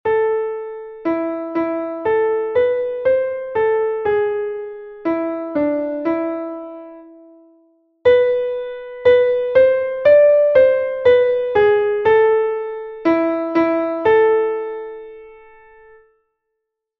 exercise 2 4th interval